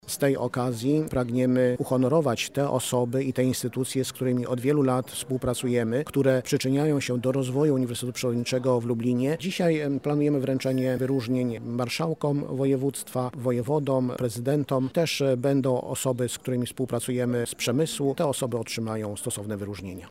Uroczystość odbyła się dziś (09.10) w Centrum Kongresowym Uniwersytetu Przyrodniczego.